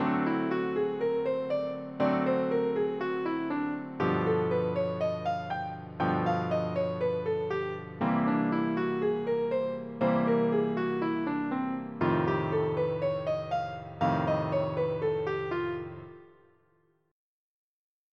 whole tone scale over rhythm changes
rhythm-changes-B-whole-tone.mp3